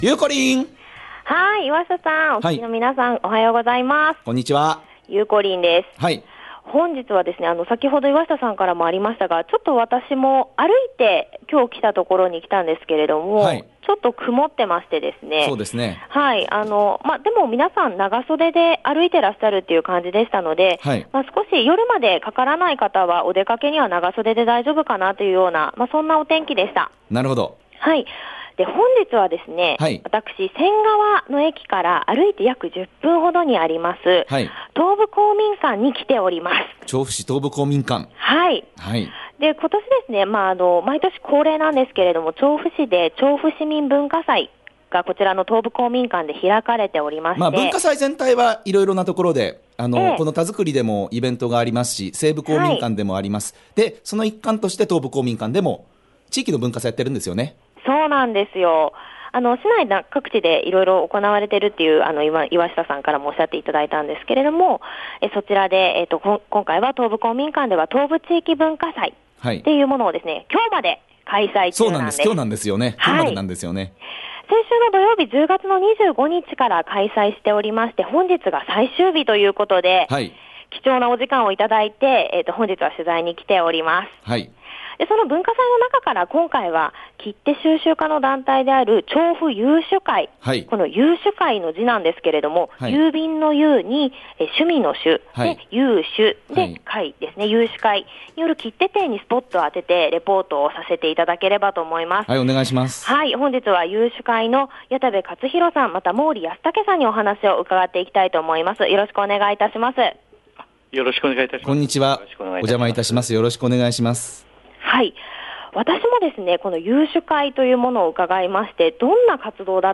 さて、今日のびゅーサン街角レポートは「調布市民文化祭」の一環で東部公民館で行われていた「東部地域文化祭」にお邪魔してきました☆